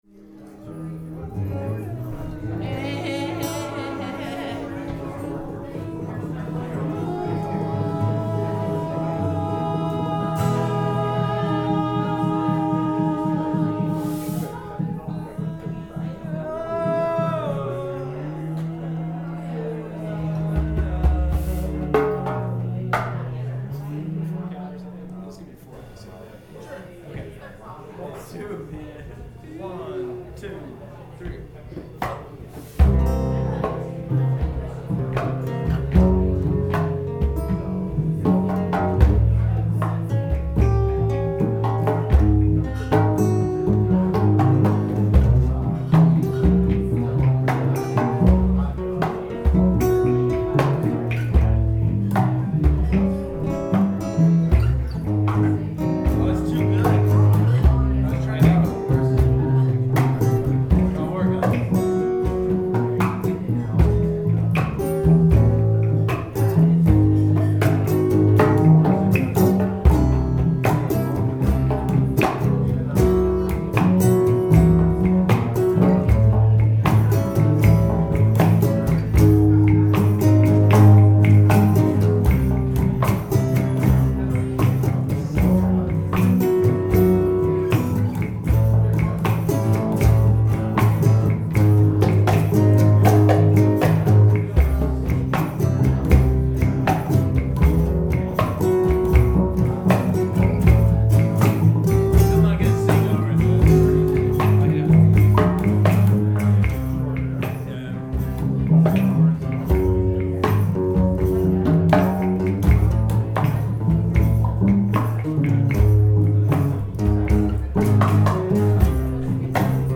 guitar and voice
ukelele and percussion
djembe and voice
bassbox, voice, and occasional trumpet